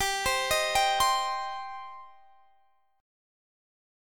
Gsus4#5 Chord
Listen to Gsus4#5 strummed